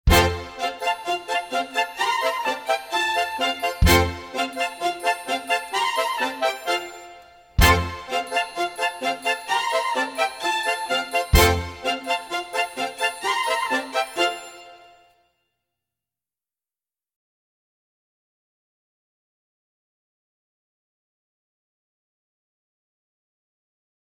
Guide Vocals